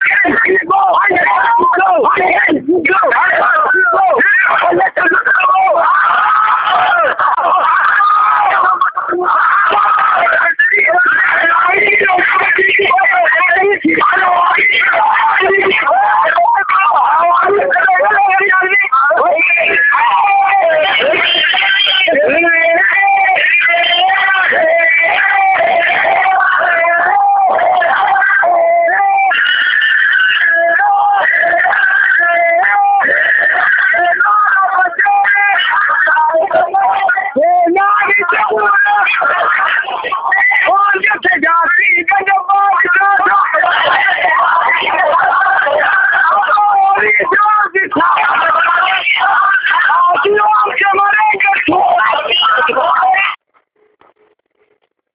Halkan edaa sa’aa 12:30 wb irraa eegaluun hanga halkan keessa sa’a 5:00tti Mooraa Yuunibarsiitii Dirree Dhawaa dhuunfachuun walleewwaan warraaqsaa ABO fi uumata Oromoo faarsuu fi qabsoo bilisummaa Oromoo leellisuun diddaa jabaa kaasan.